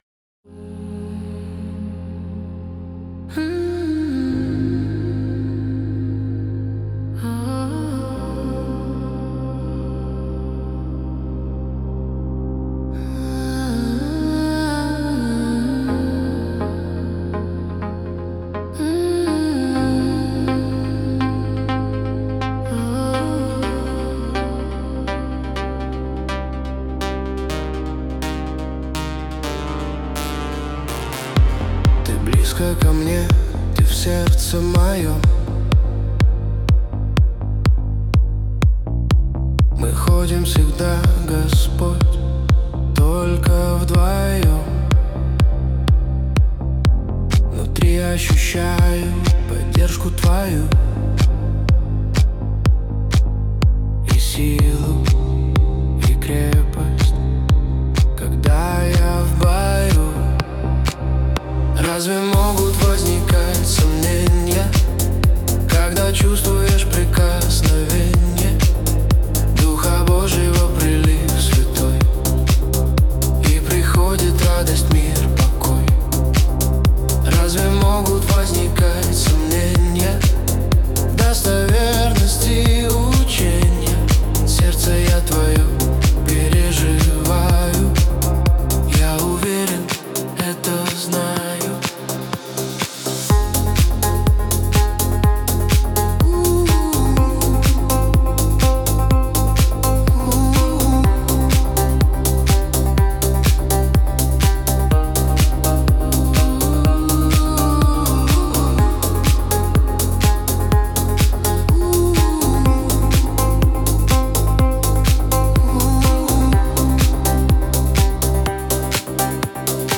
48 просмотров 228 прослушиваний 17 скачиваний BPM: 124